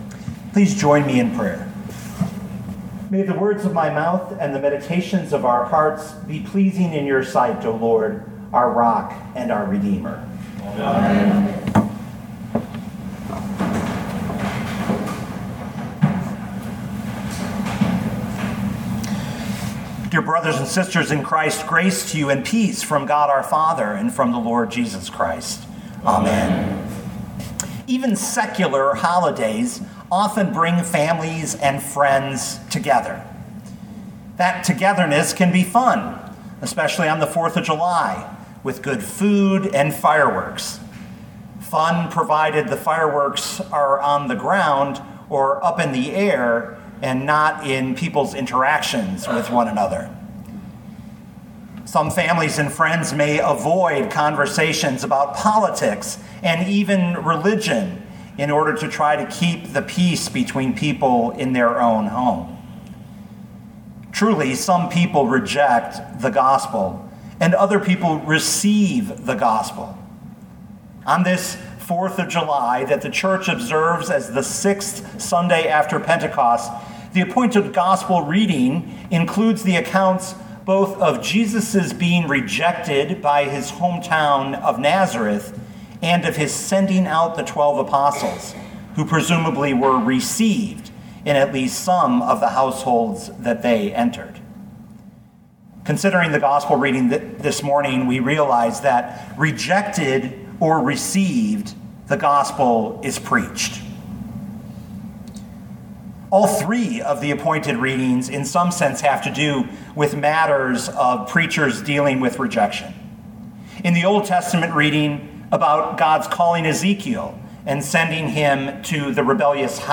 rejected-or-received-the-gospel-is-preached.mp3